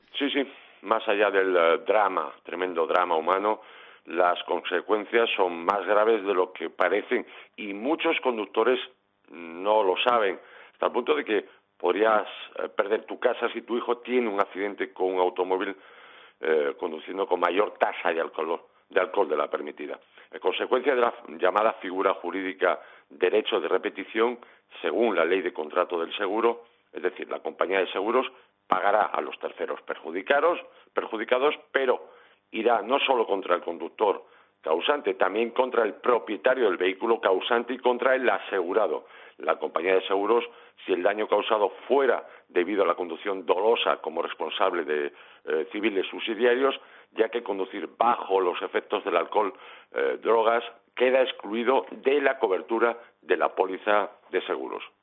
La consulta de un oyente de Poniendo las Calles a Carlos Moreno 'El Pulpo' indaga en esta situación que se podrá dar en muchas familias